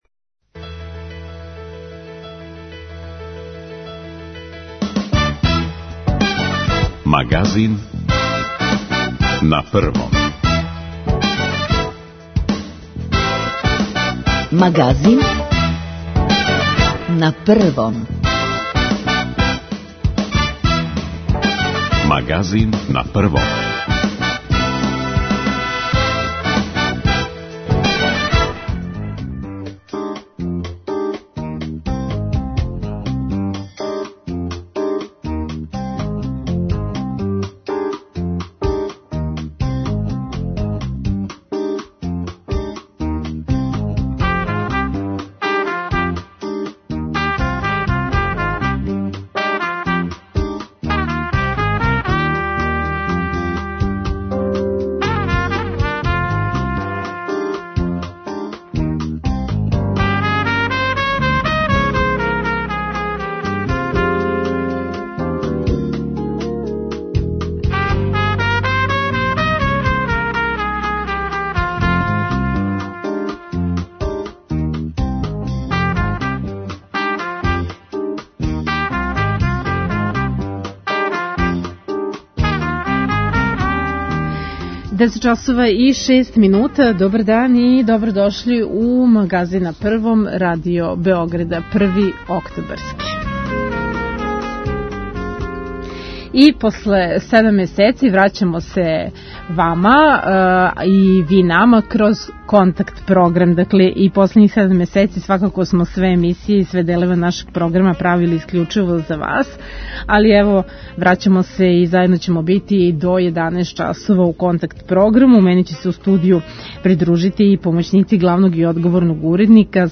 Први сат отворен је за укључења слушалаца.